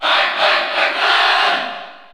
Crowd cheers (SSBU) You cannot overwrite this file.
Pac-Man_Cheer_French_PAL_SSBU.ogg